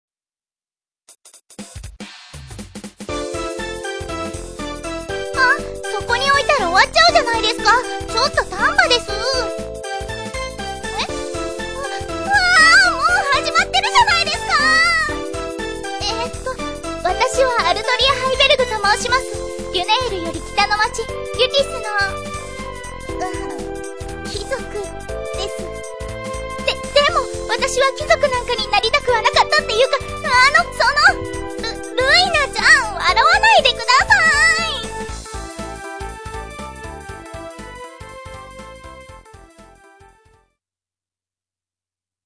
自己紹介ボイス"